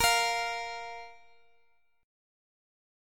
Bb5 Chord
Listen to Bb5 strummed